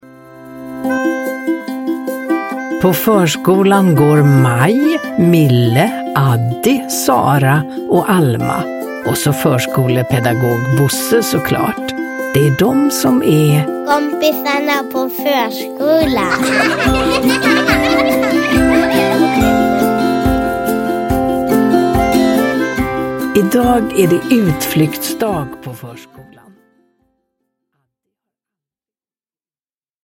Addi går på utflykt – Ljudbok
Uppläsare: Ulla Skoog